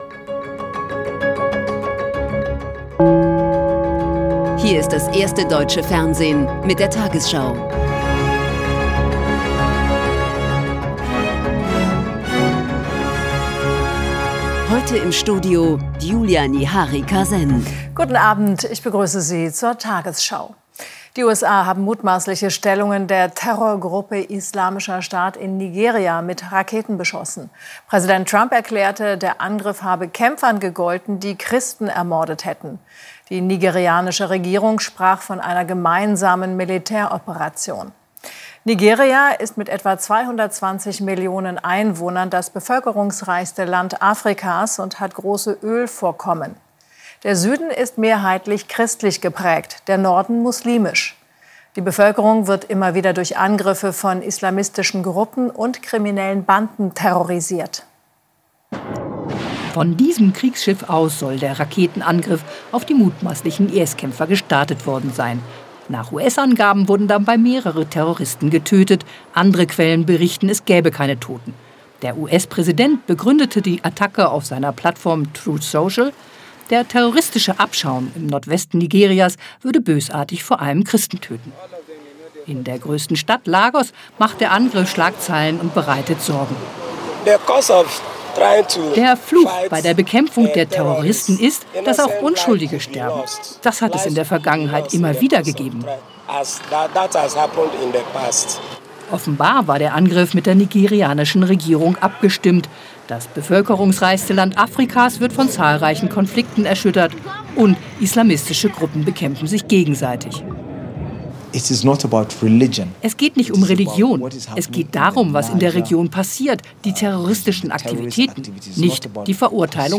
tagesschau 20:00 Uhr, 26.12.2025 ~ tagesschau: Die 20 Uhr Nachrichten (Audio) Podcast